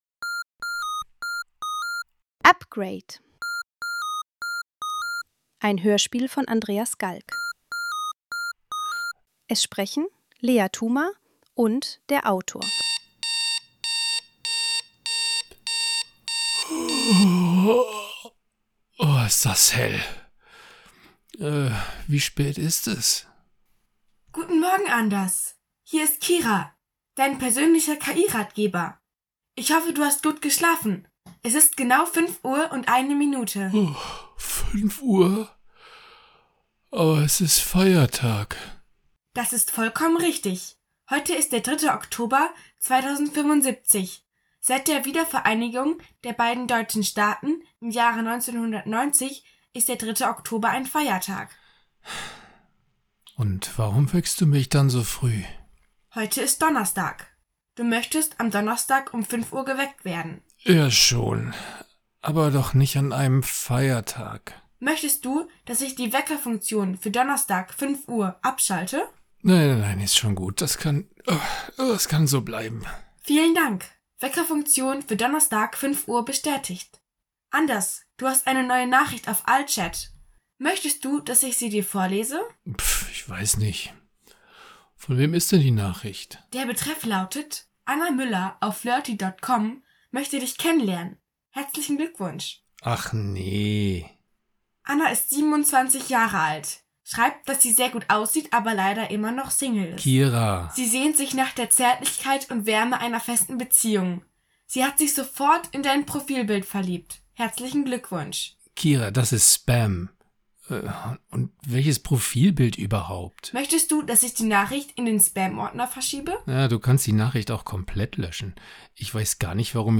Er hatte vergessen, ihr mitzuteilen, dass ein Feiertag bevorsteht. Dieses kleine Versehen hat ungeahnte Auswirkungen. Hinweis: Es wirken ausschließlich menschliche SprecherInnen mit. E wurden keine KI-Stimmen benutzt.